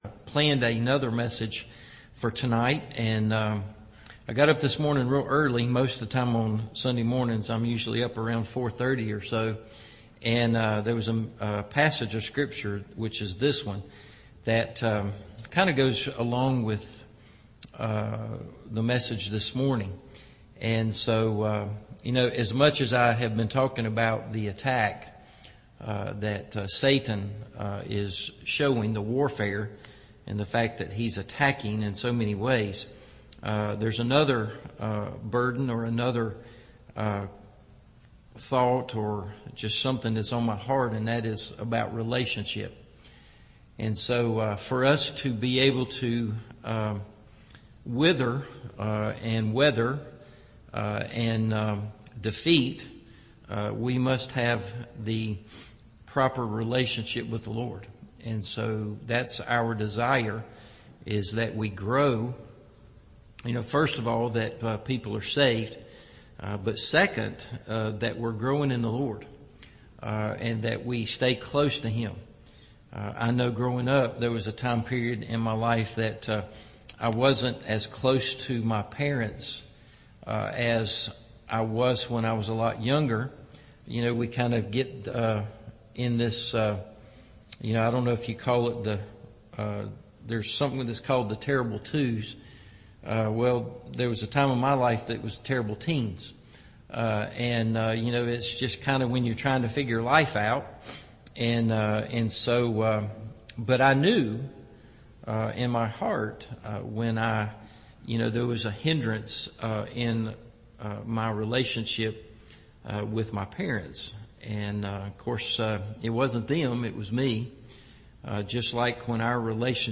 Burning In Our Hearts – Evening Service